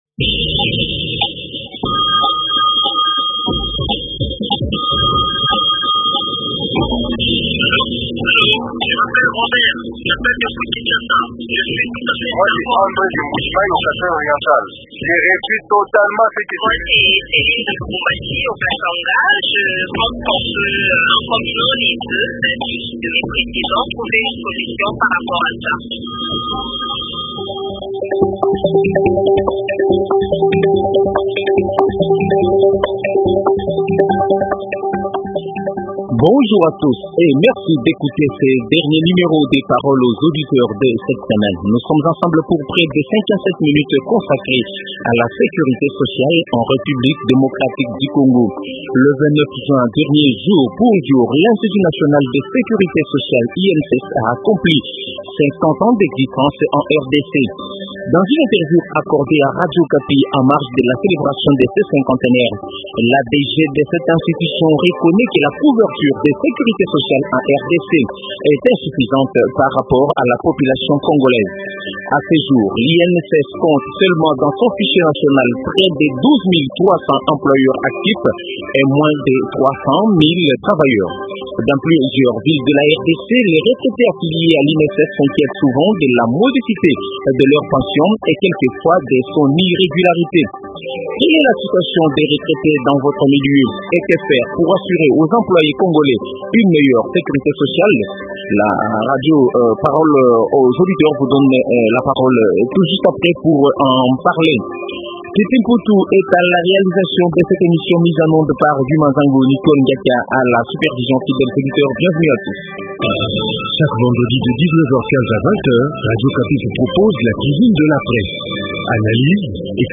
Télécharger Parole aux auditeurs de lundi 11 juillet sera consacrée à la campagne avant l’heure que mènent certains acteurs politiques.